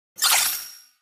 Grito de Meltan.ogg
Grito_de_Meltan.ogg